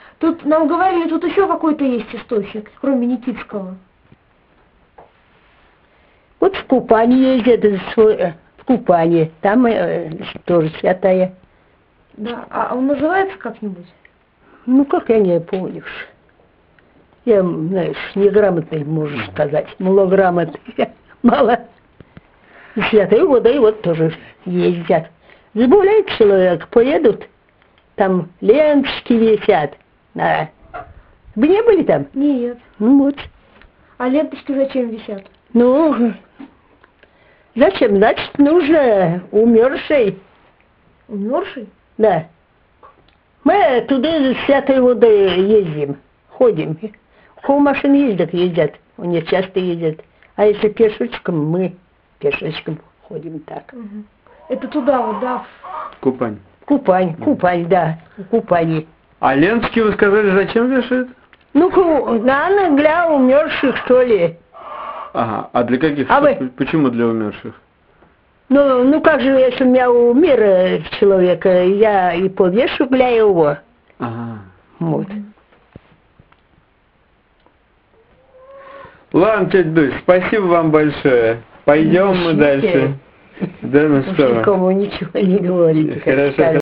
Место фиксации: Ярославская область, Переславский район, деревня Криушкино